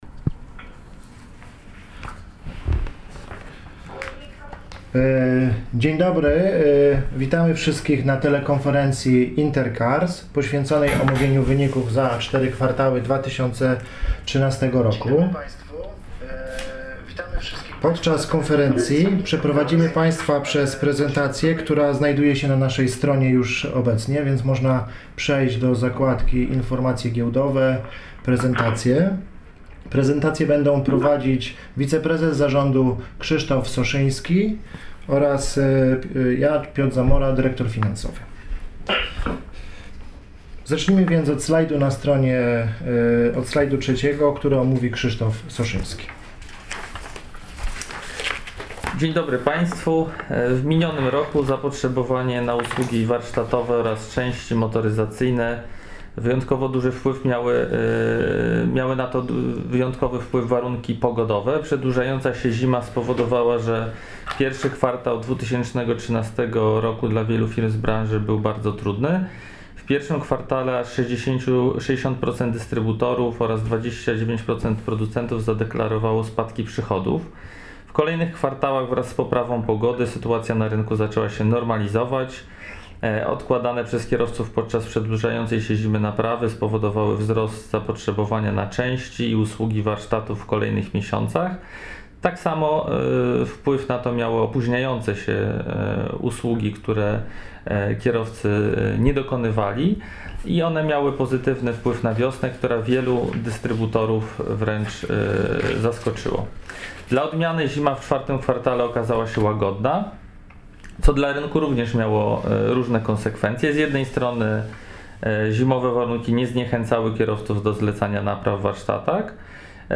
Trzecia telekonferencja z udziałem władz Spółki Inter Cars SA | Relacje Inwestorskie - Inter Cars SA
telekonferencja_marzec_2014.mp3